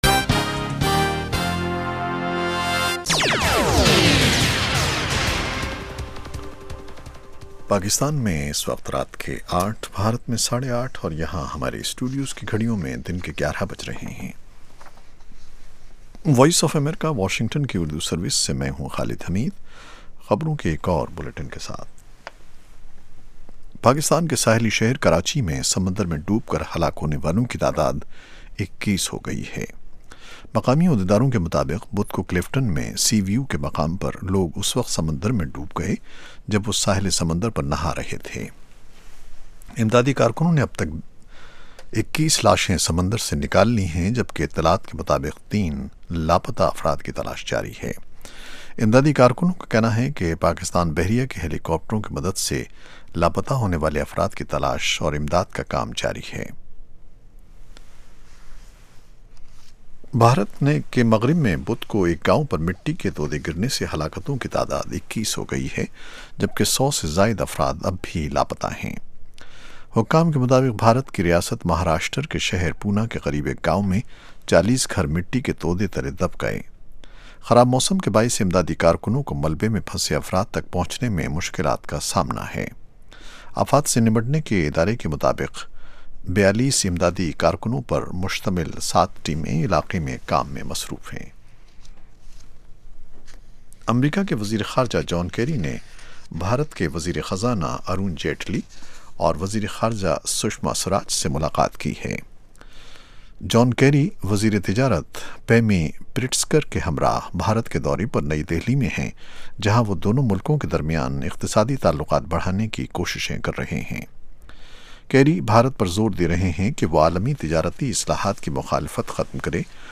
In The News: 8:00PM PST ایک گھنٹے دورانیے کے اس پروگرام میں خبروں کے علاوہ مہمان تجزیہ کار دن کی اہم خبروں کا تفصیل سے جائزہ لیتے ہیں اور ساتھ ہی ساتھ سننے والوں کے تبصرے اور تاثرات بذریعہ ٹیلی فون پیش کیے جاتے ہیں۔